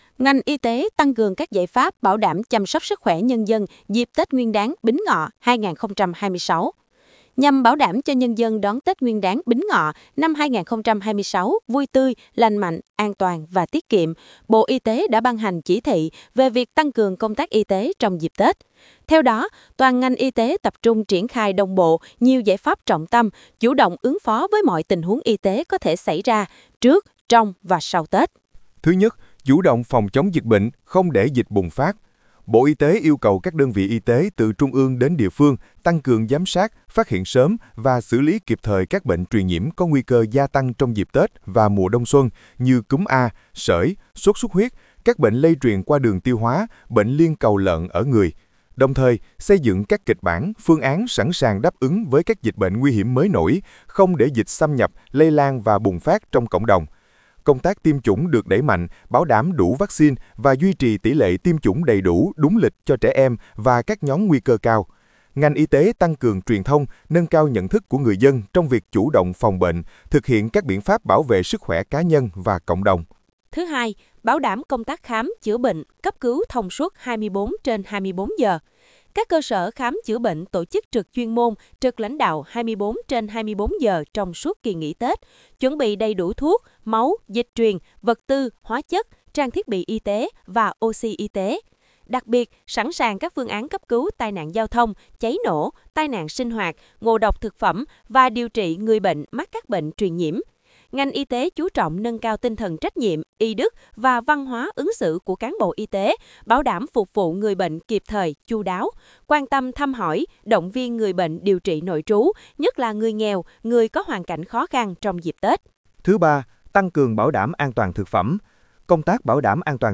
file phat thanh.wav